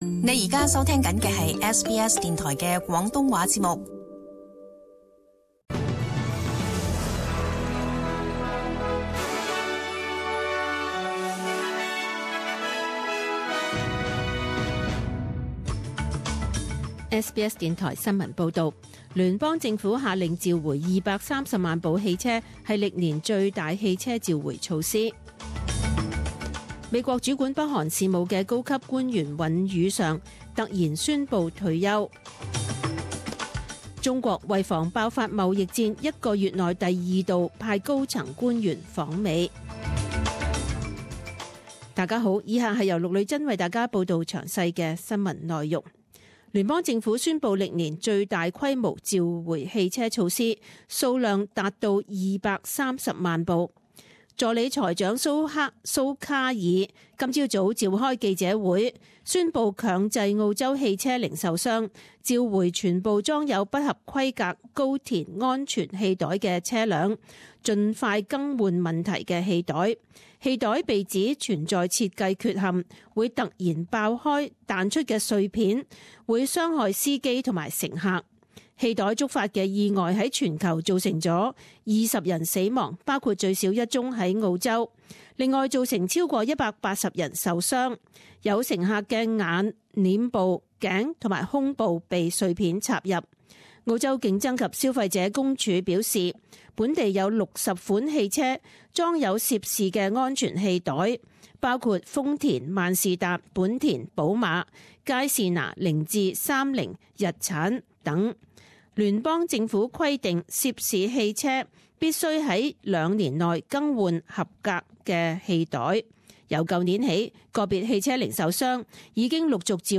Detailed morning news bulletin